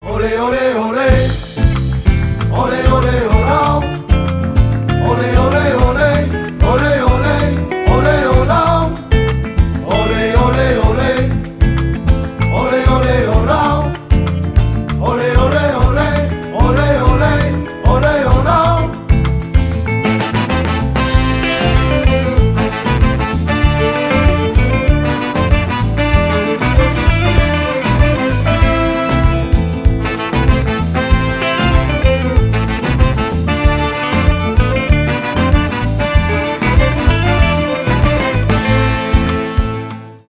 Инструментальные рингтоны
этнические